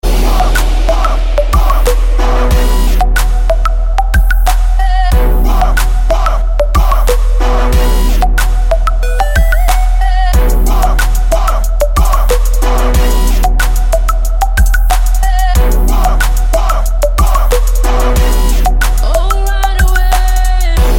Категория - клубные.